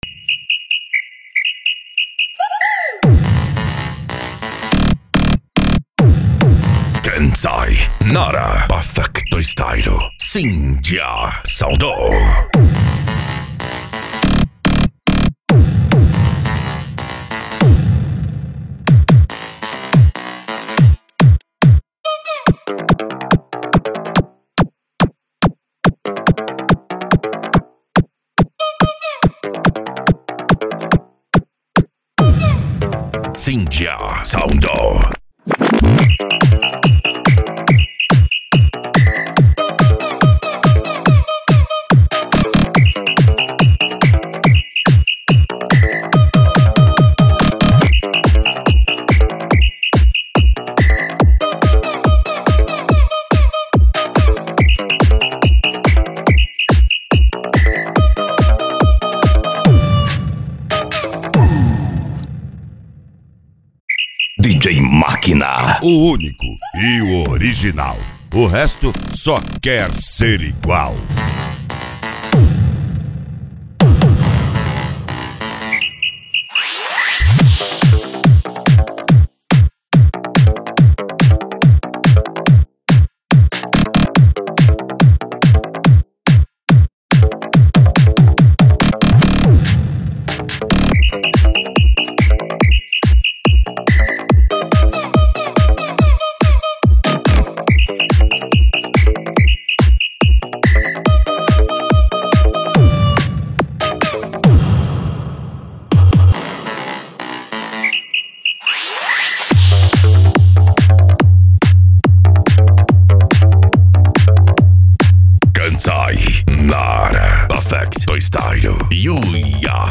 Deep House
Eletronica
Hard Style
PANCADÃO